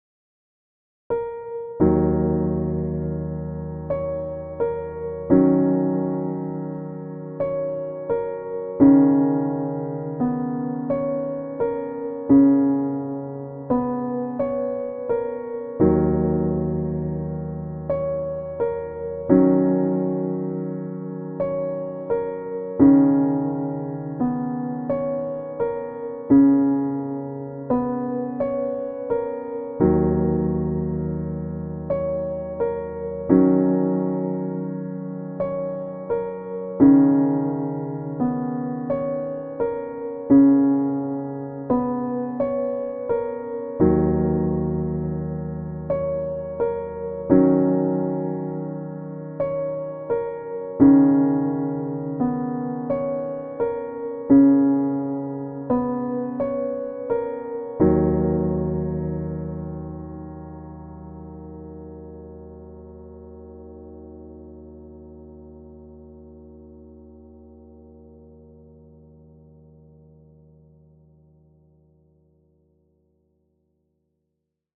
In particular, the velocity of the piano and the sustain pedal are not yet influenced by the progressive harmonic structure.